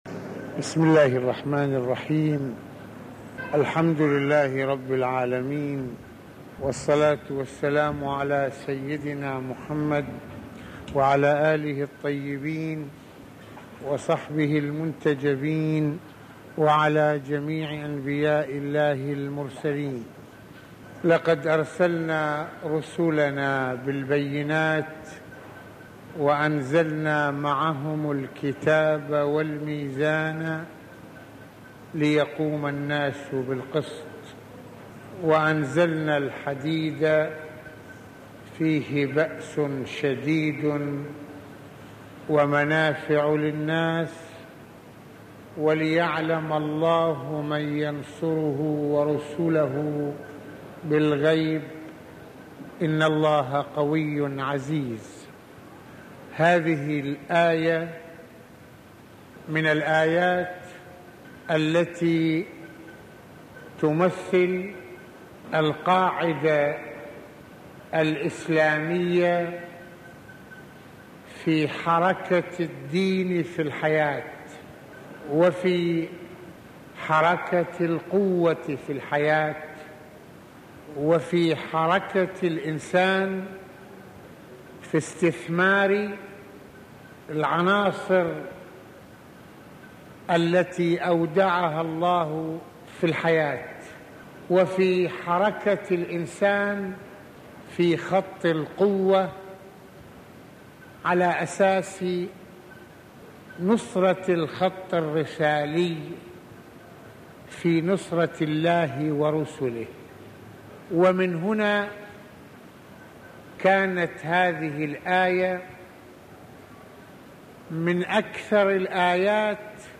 - يتحدث المرجع السيد محمد حسين فضل الله (رض) في هذه المحاضرة القرآنية عن حركة الدين في الحياة بما ينسجم مع إرادة الله تعالى القائمة على تكريس العدل والقسط ، ويشير سماحته (رض) إلى الجدل الحاصل حول علاقة السياسة بالدين ومحاولات البعض فصل السياسة عن الدين والنظرة الإسلامية للمسألة والتي تعتبر السياسة جزءً حيويا من المنظومة الدينية الهادفة إلى إقامة العدل على الأرض ...